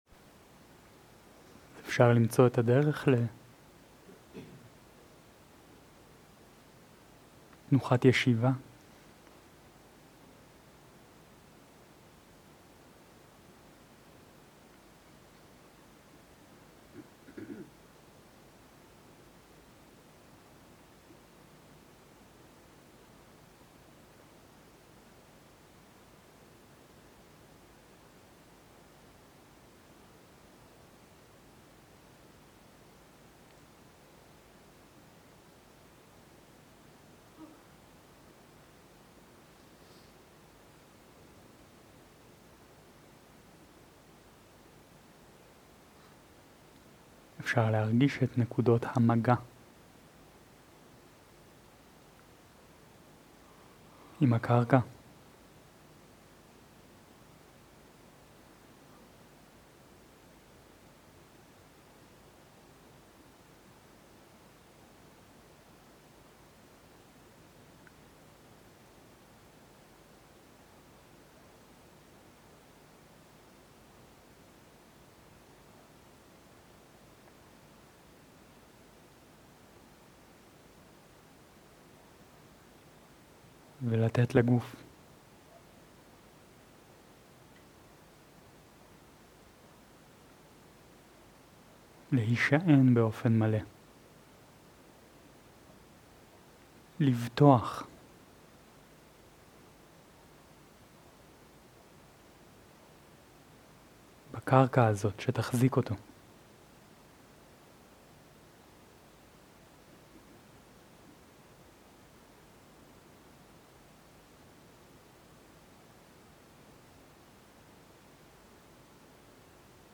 מדיטציית מטא מונחית
Dharma type: Guided meditation שפת ההקלטה